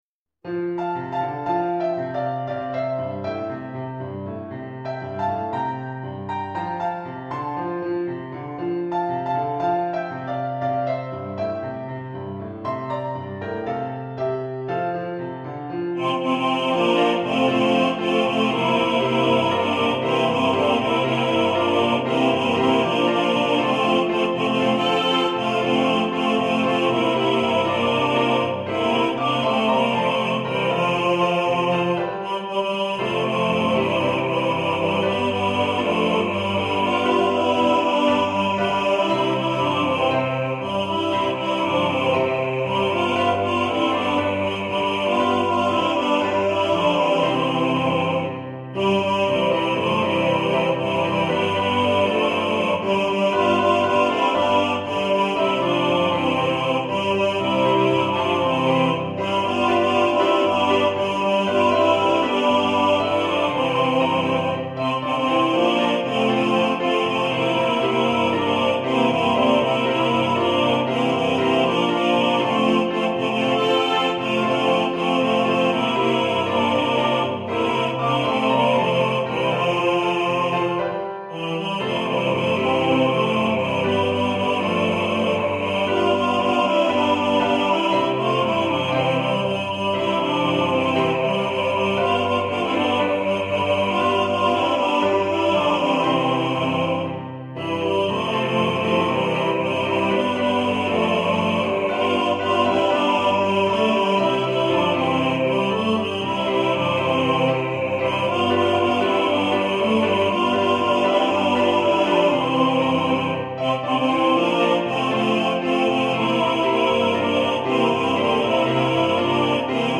Hier erhältlich in der dreistimmigen Version!